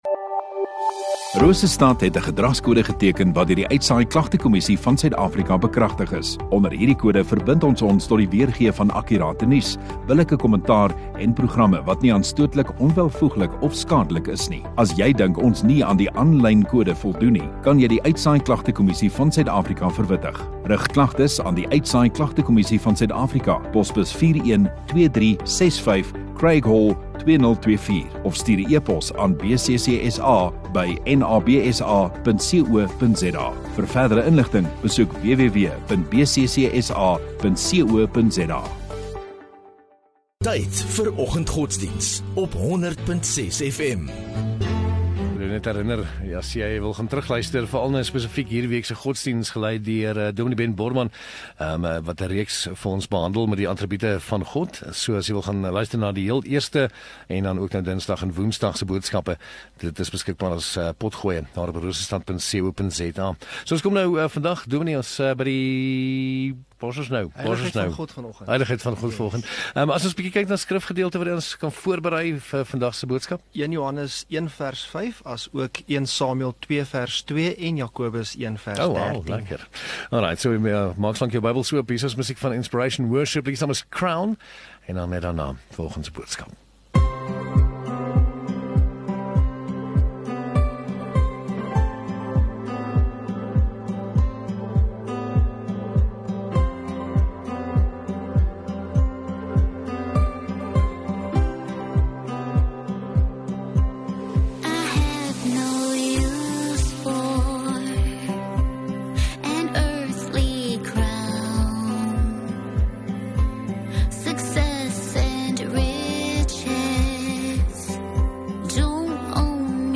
23 May Donderdag Oggenddiens